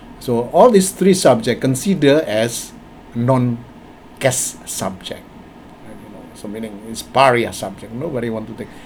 S1 = Indonesian male S2 = Malaysian female
But the use of [s] instead of [ʃ] at the end of the word resulted in her being unable to understand the word.
One might note that S2 had no problem understanding pariah with the stress on the first syllable; but she could not understand cash with [s] rather than [ʃ] at the end.